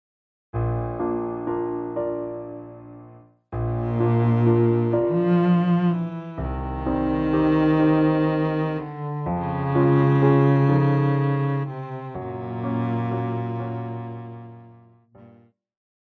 Classical
Cello
Piano
Instrumental
Solo with accompaniment